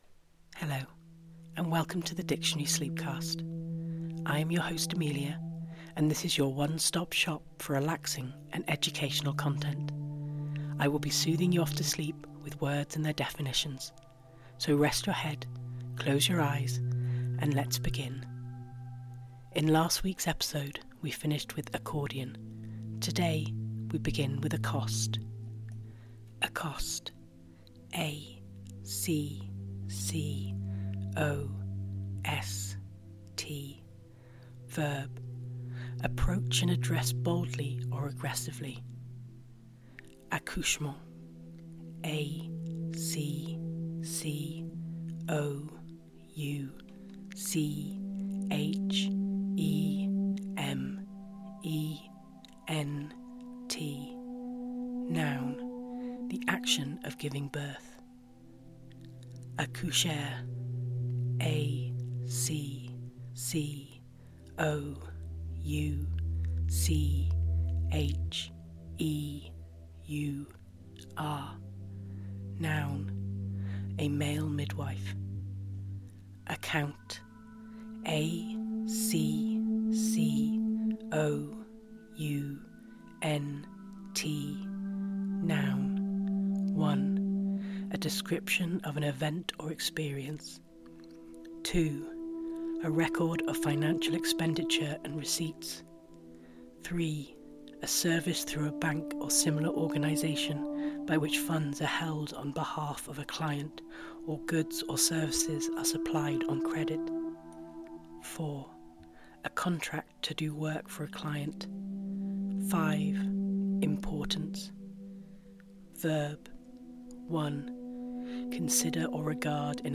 Relax and drift off to sleep to the sound of someone reading words and their definitions to you.
Can't sleep, or just need to listen to something restful, this is the podcast for you.